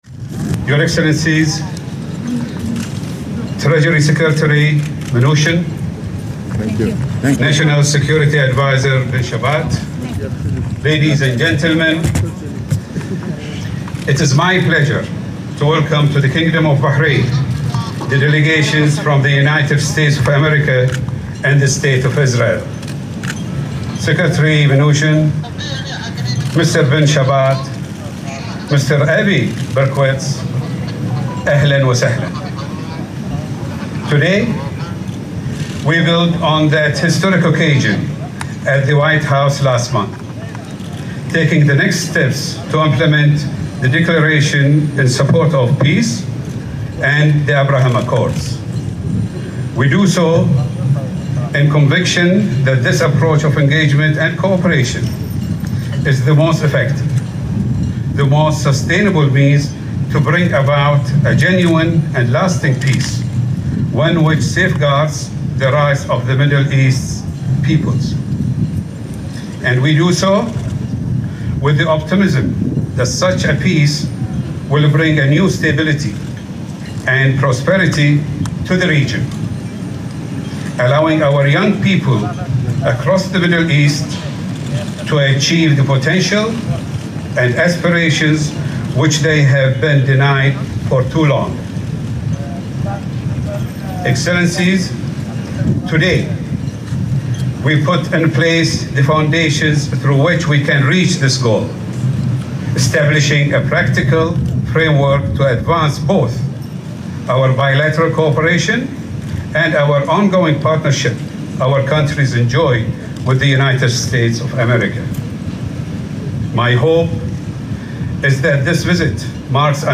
El Al Flight 973 Airport Arrival Ceremony Address
delivered 18 October 2020, Muharraq Island, Bahrain